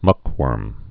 (mŭkwûrm)